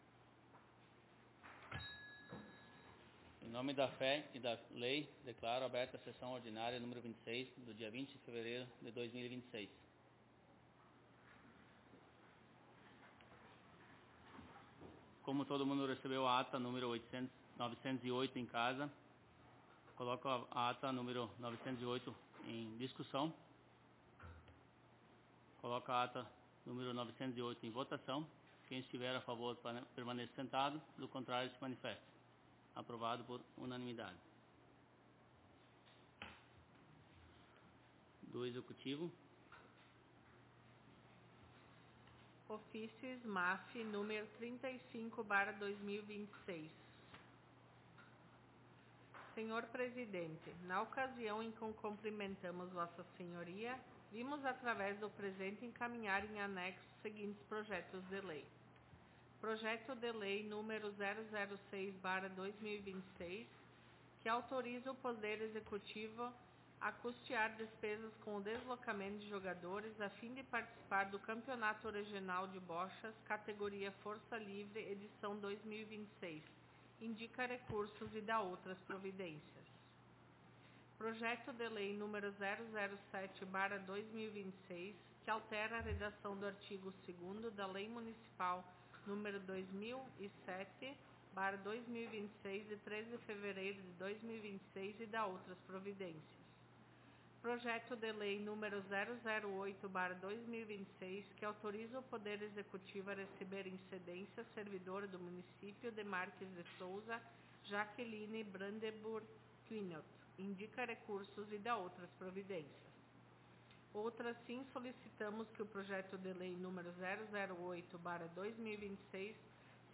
Aos 20 (vinte) dias do mês de fevereiro do ano de 2026 (dois mil e vinte e seis), na Sala de Sessões da Câmara Municipal de Vereadores de Travesseiro/RS, realizou-se a Vigésima Sexta Sessão Ordinária da Legislatura 2025-2028.